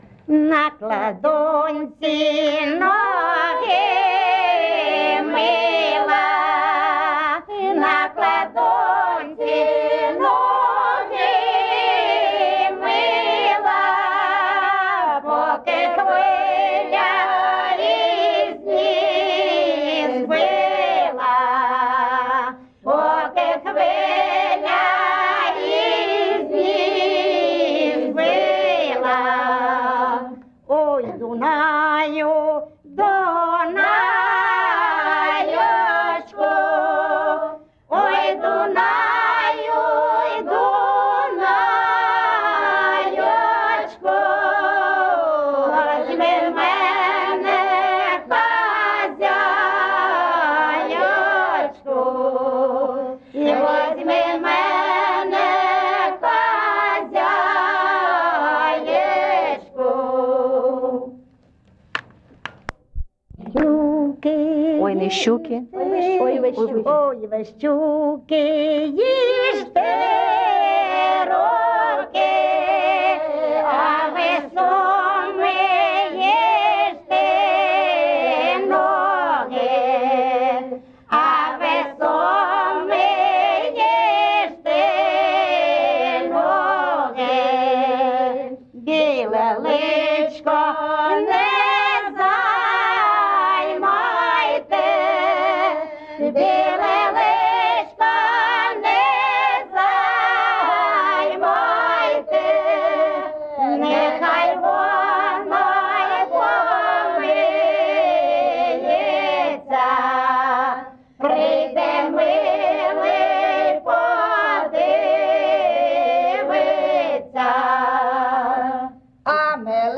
ЖанрПісні з особистого та родинного життя, Балади
Місце записус. Писарівка, Золочівський район, Харківська обл., Україна, Слобожанщина